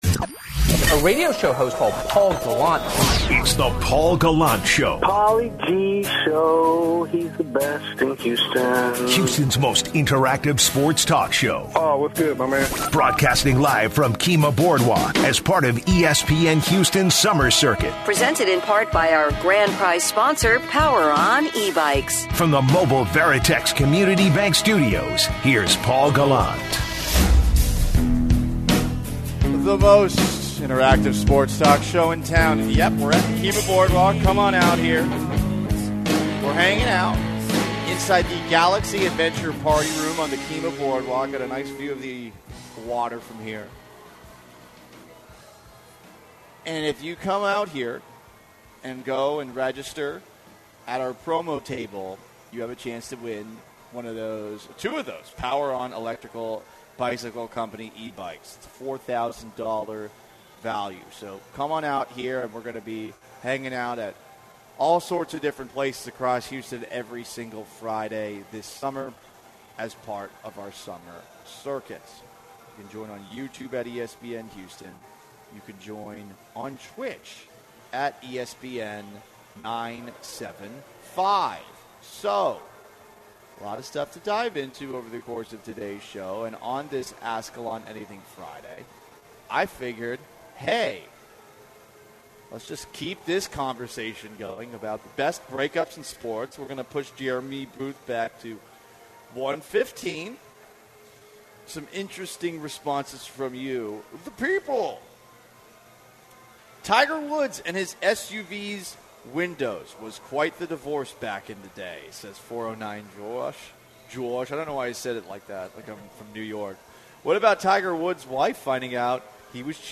live from Kemah Boardwalk